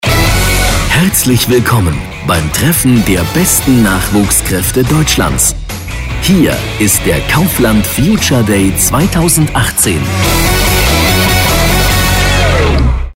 Showansage
Unterlegt mit der passenden Musik wird Ihr Event garantiert ein Volltreffer!